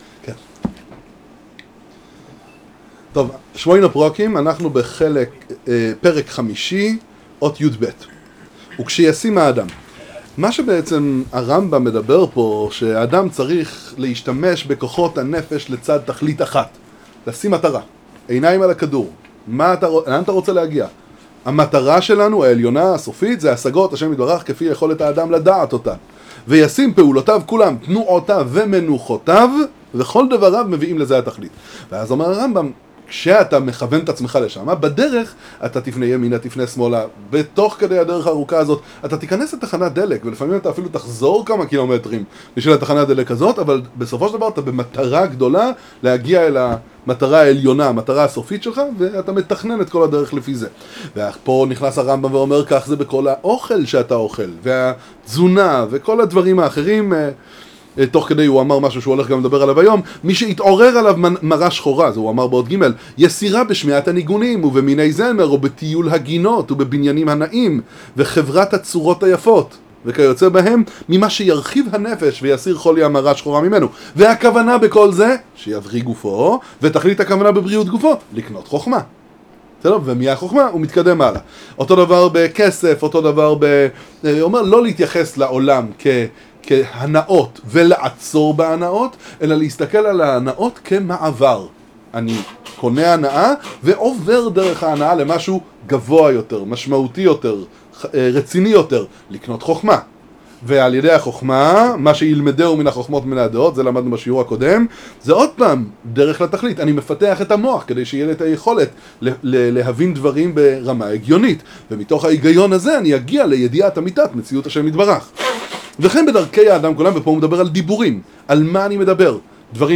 שיעור בספר שמונה פרקים לרמב"ם